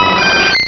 pokeemerald / sound / direct_sound_samples / cries / chansey.aif